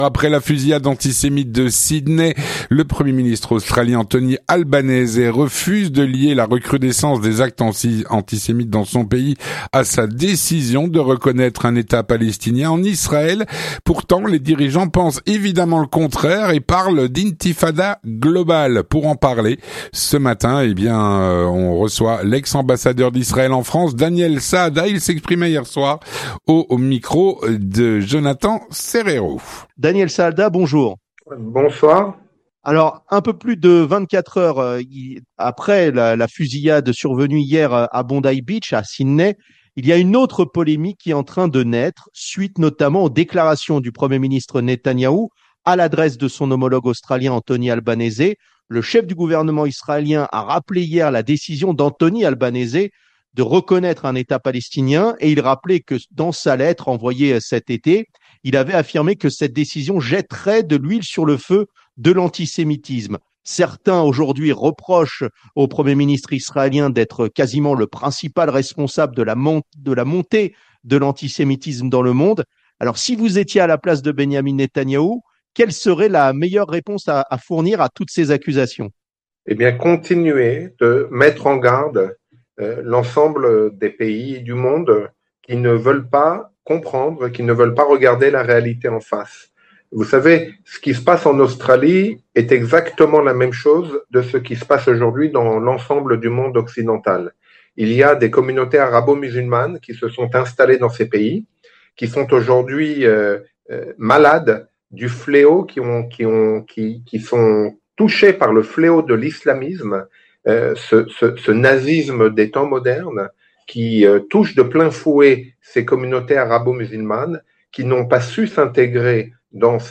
L'entretien du 18H - Le Premier Ministre australien, Anthony Albanese, refuse de lier la recrudescence des actes antisémites à la reconnaissance d'un État Palestinien.
Pour en parler nous recevons l'ex-ambassadeur d'Israël en France, Daniel Saada.